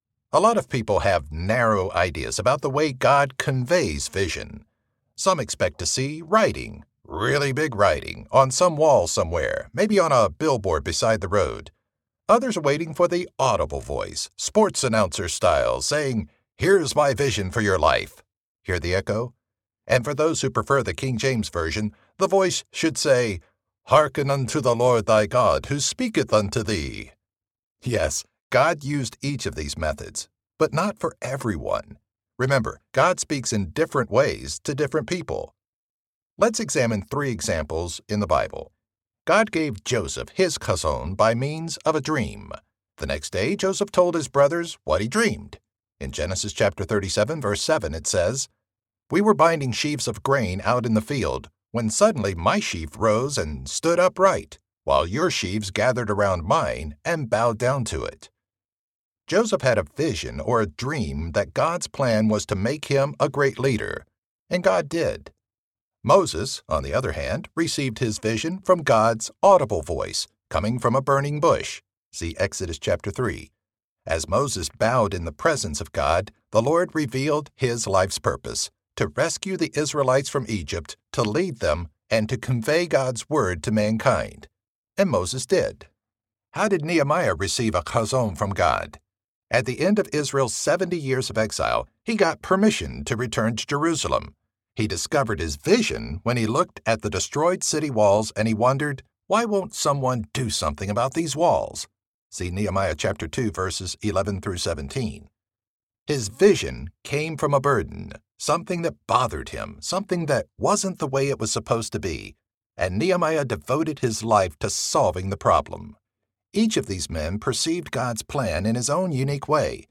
Chazown, Revised and Updated Edition Audiobook
Narrator
5.42 Hrs. – Unabridged